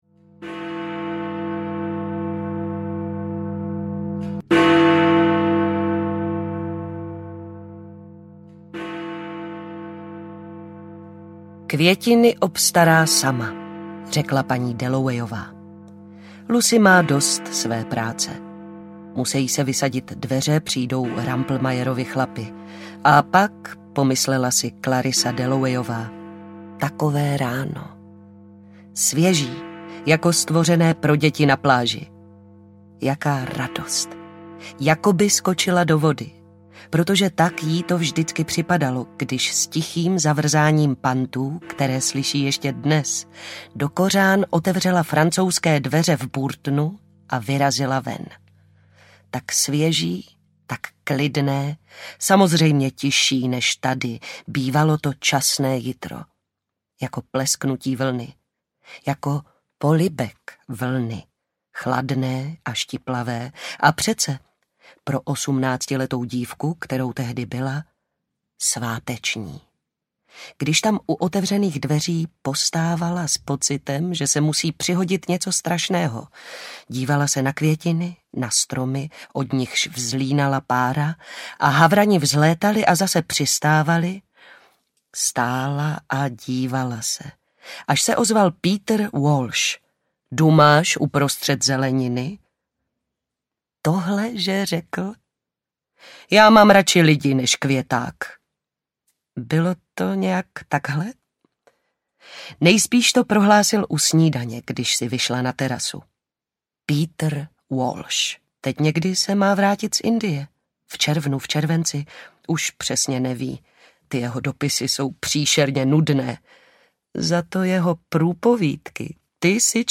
Paní Dallowayová audiokniha
Ukázka z knihy